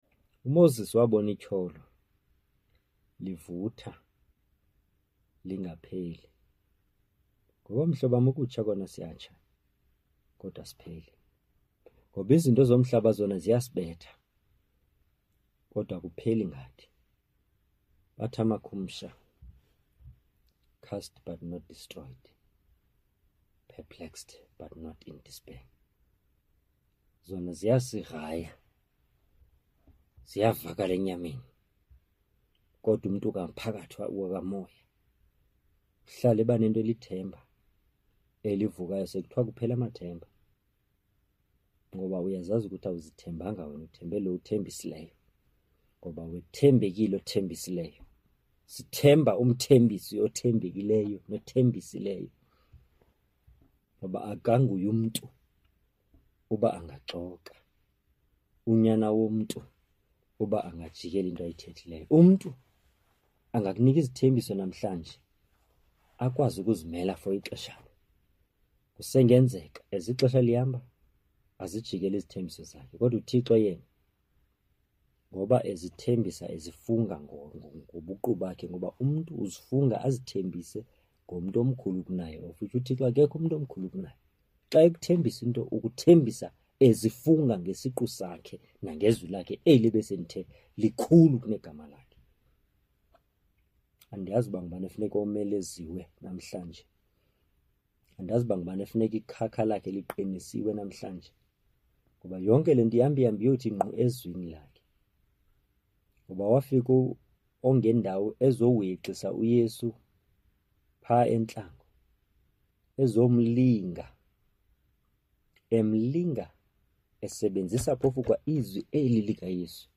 Radio Pulpit Program where I share God's word through Preaching, Teaching and Motivation using my mother tongue (isiXhosa)